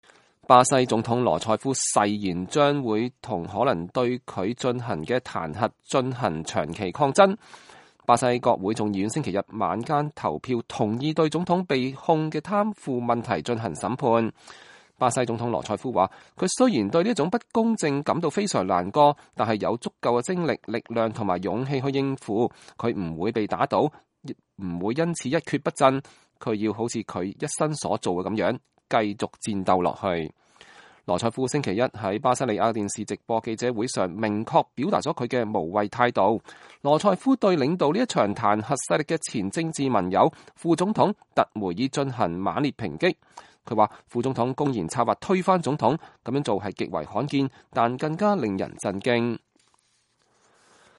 羅塞夫星期一在巴西利亞的電視直播記者會上明確表達了她的無畏態度。